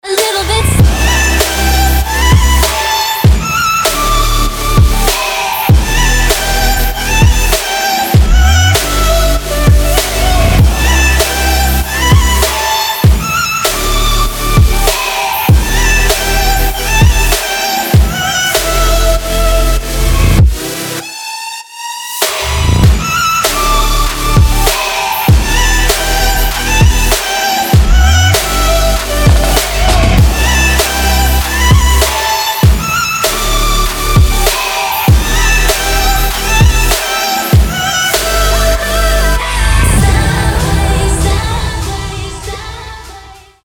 • Качество: 320, Stereo
громкие
dance
EDM
future bass
vocal